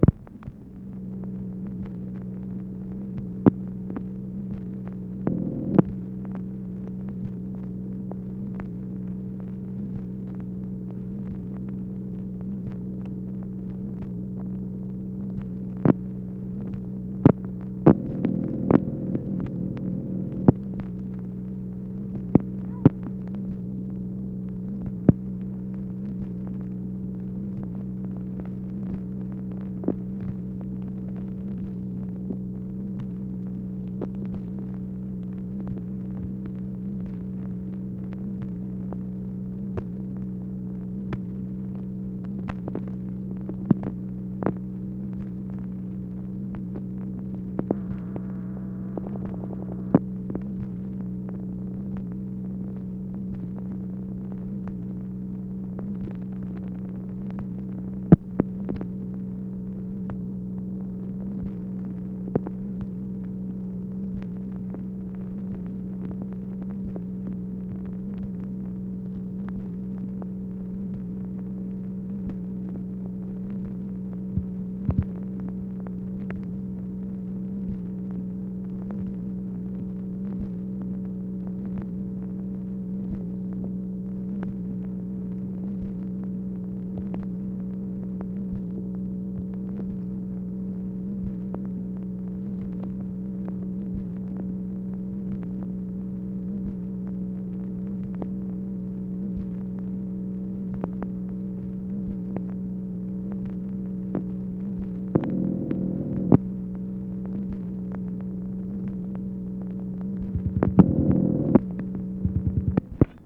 MACHINE NOISE, January 29, 1964
Secret White House Tapes | Lyndon B. Johnson Presidency